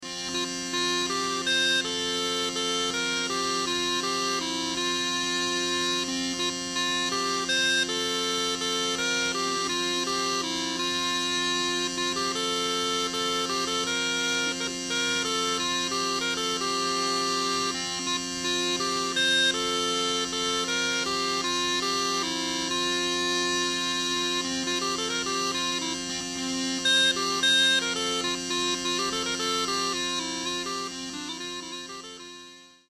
Ashington Folk Club - Spotlight 20 October 2005
Northumbrian pipe time
First, in romantic mood, we had 'Fond Heart' and 'Joys of Wedlock' followed by 16th and 17th century English dance tunes.